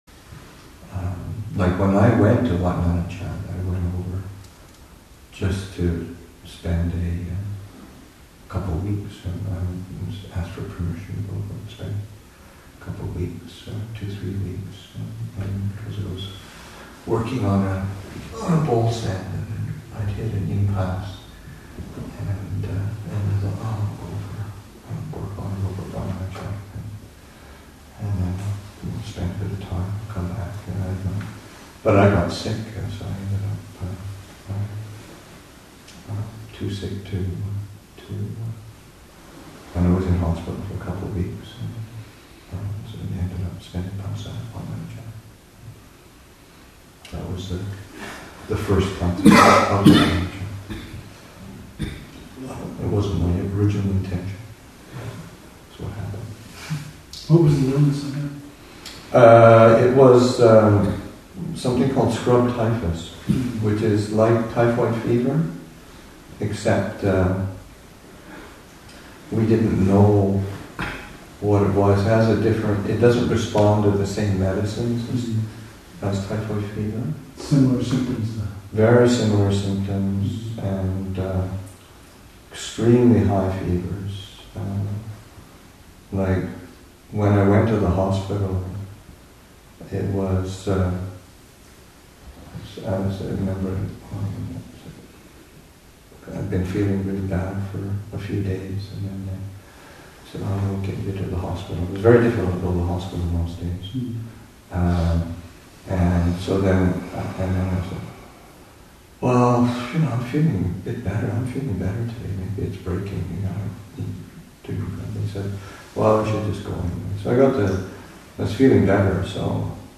An extended discussion ensues.